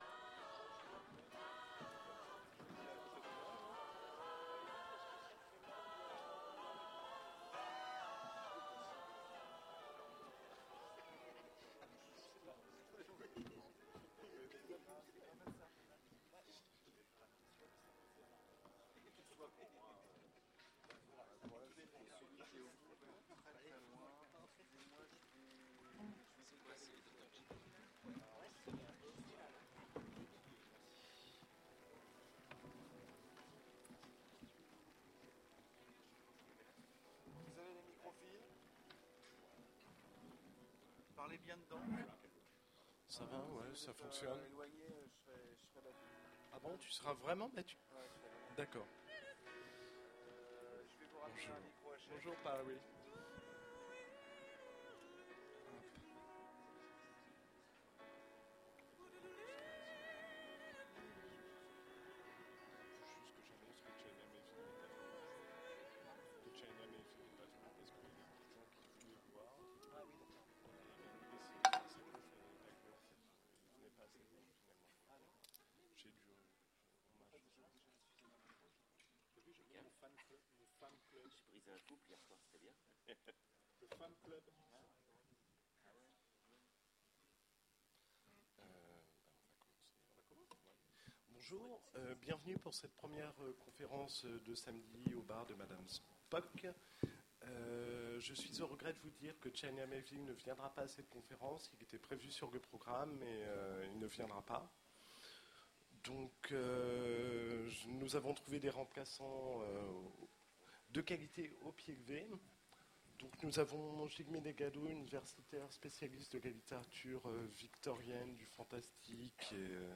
Utopiales 2010 : Conférence de Docteur Jekyll à Mister Hyde